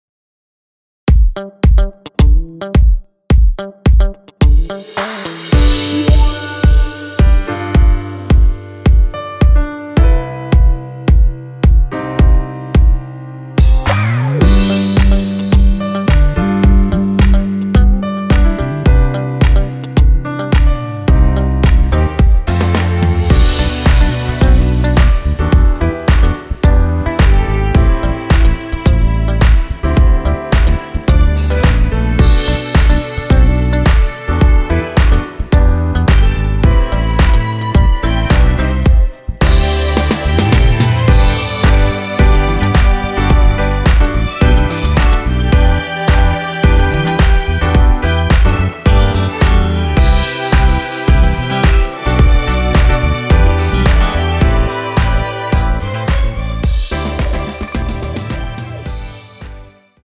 원키에서(+3)올린 MR입니다.
◈ 곡명 옆 (-1)은 반음 내림, (+1)은 반음 올림 입니다.
앞부분30초, 뒷부분30초씩 편집해서 올려 드리고 있습니다.
중간에 음이 끈어지고 다시 나오는 이유는